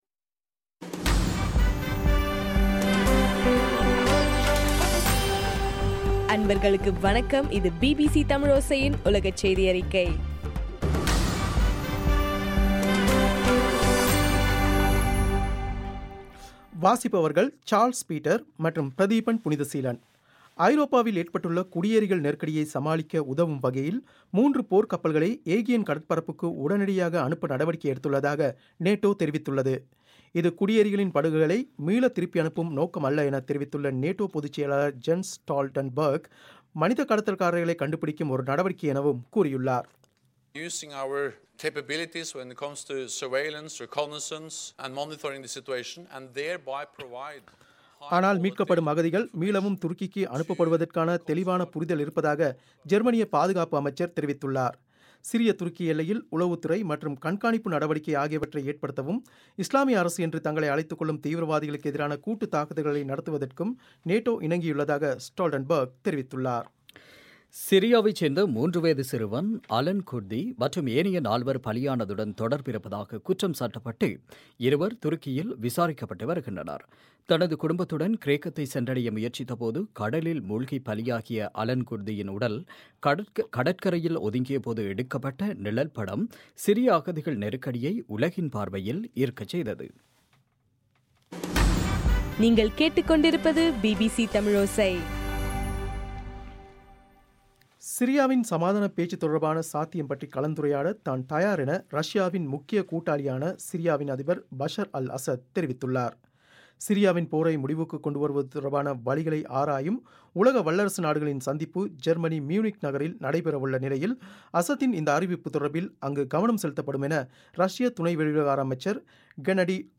பிப்ரவரி 11 பிபிசியின் உலகச் செய்திகள்